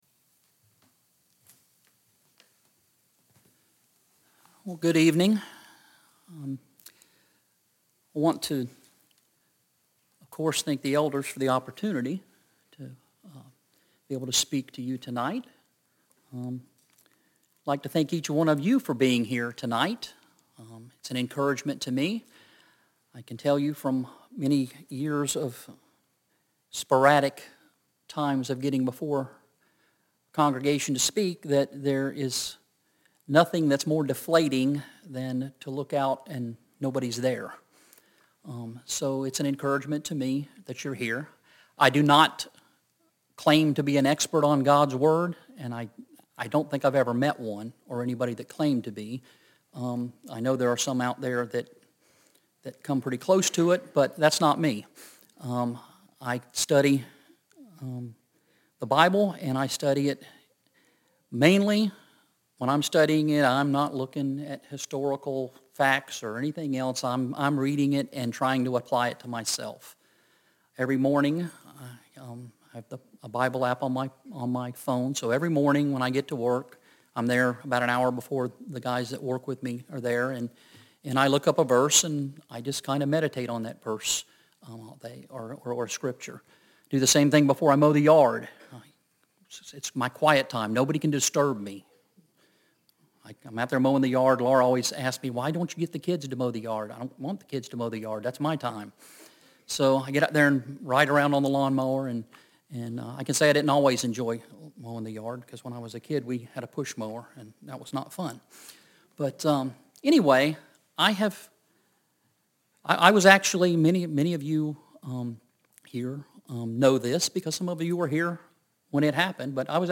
Sun PM Sermon